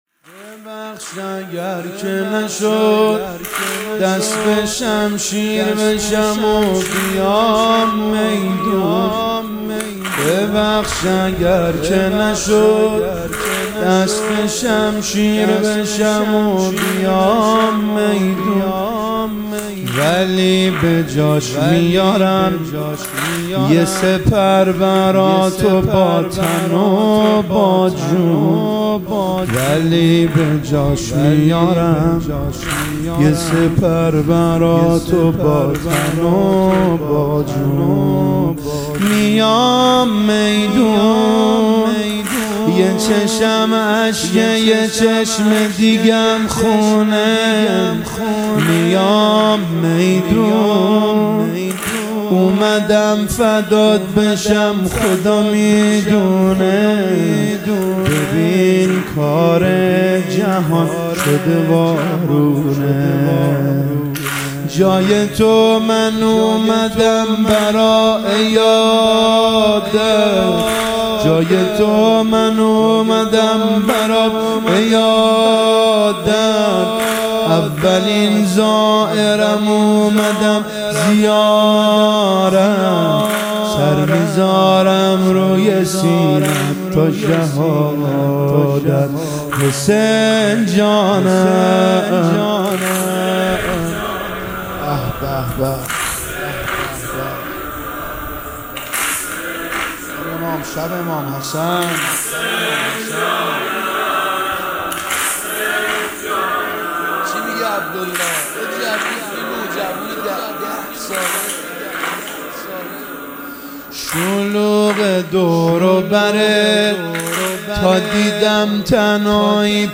شب پنجم محرم 1399هیئت جنت العباس(ع) قم